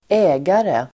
Uttal: [²'ä:gare]